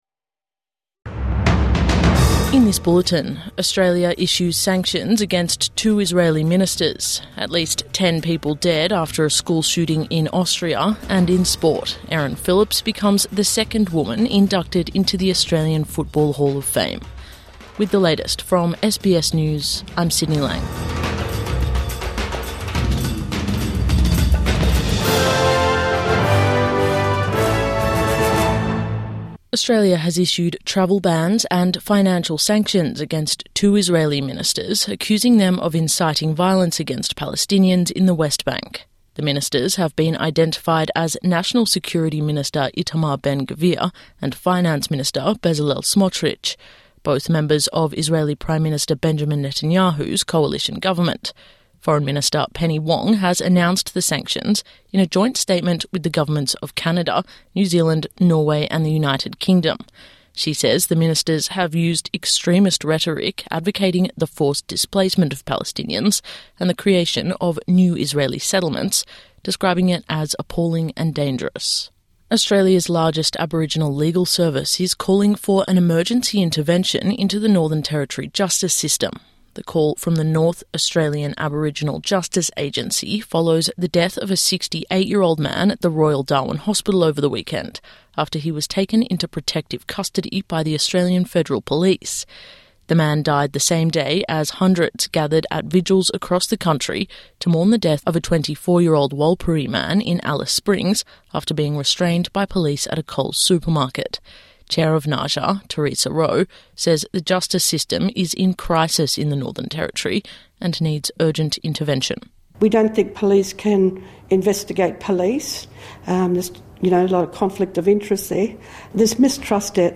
Ten dead in school shooting in Austria | Morning News Bulletin 11 June 2025